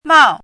chinese-voice - 汉字语音库
mao4.mp3